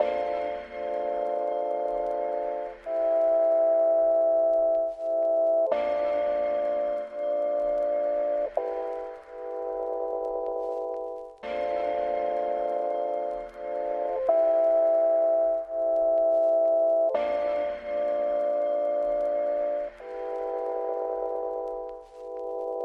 dirty q tip keys_84bpm.wav